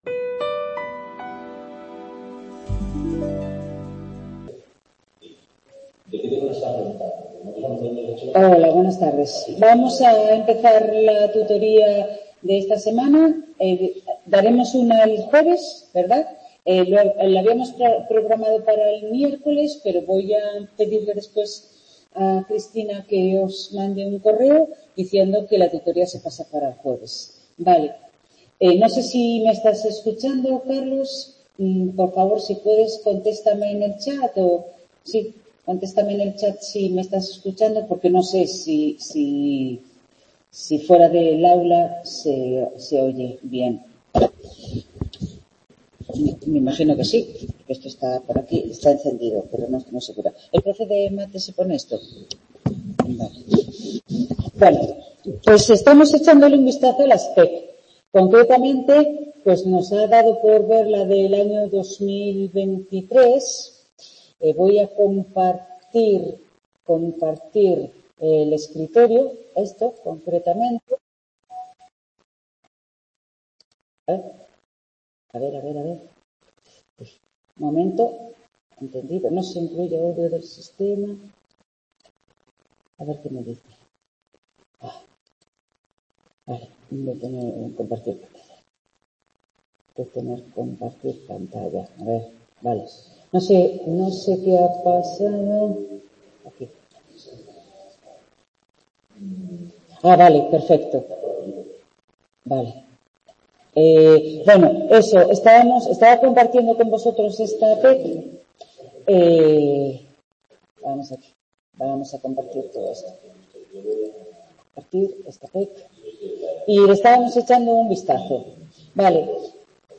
Tutoría 11/12/23 Microeconomía 2º ADE | Repositorio Digital